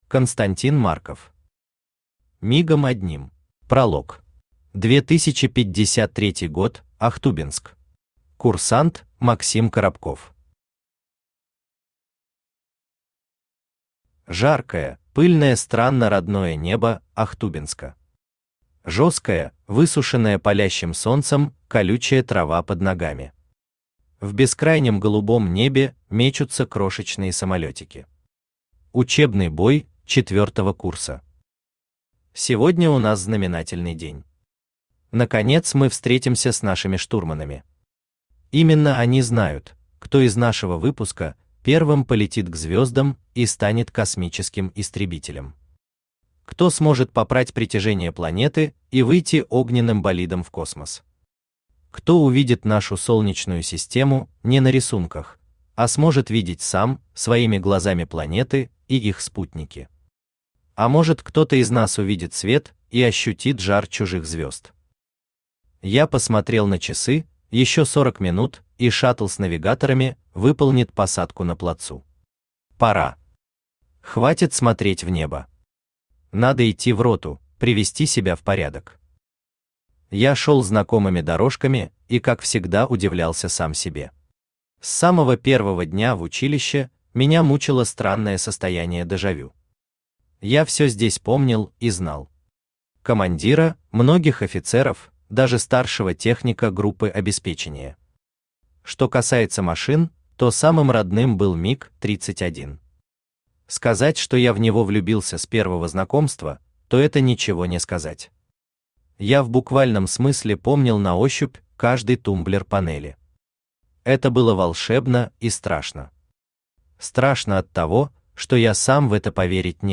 Аудиокнига Мигом одним | Библиотека аудиокниг
Aудиокнига Мигом одним Автор Константин Марков Читает аудиокнигу Авточтец ЛитРес.